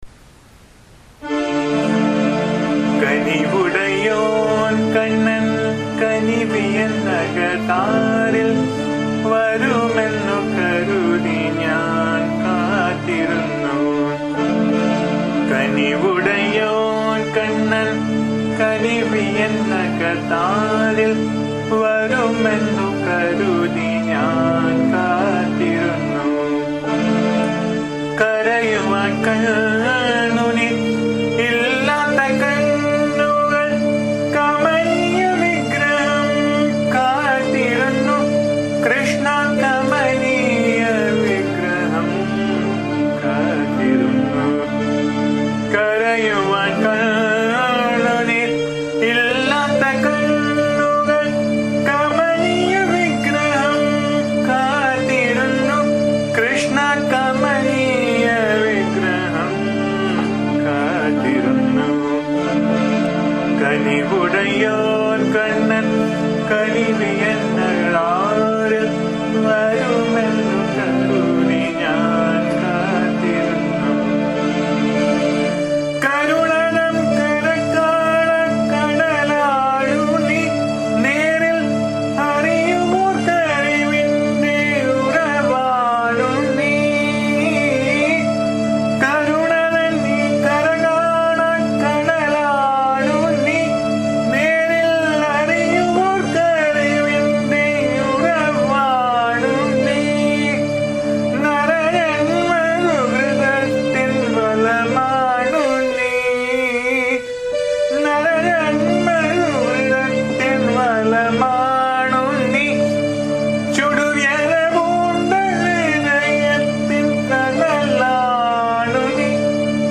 The song is set in Saramathi raga.
bhajan song